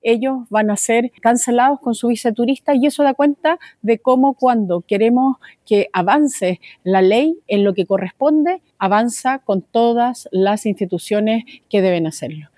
Finalmente, la subsecretaria de Turismo, Verónica Pardo, confirmó que los rusos involucrados en esta situación serán expulsados del país.